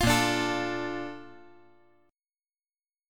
BbM7 Chord
Listen to BbM7 strummed